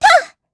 Gremory-Vox_Attack3_kr.wav